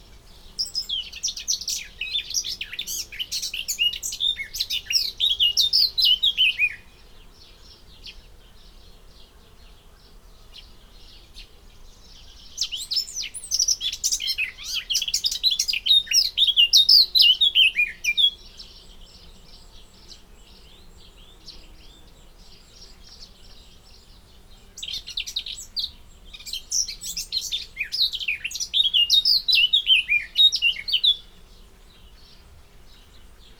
Mönchsgrasmücke Gesang
• Sie gehört zu den besten Sängern Europas.
• Ihr Gesang ist individuell erkennbar.
Moenchsgrasmuecke-Gesang-Voegel-in-Europa.wav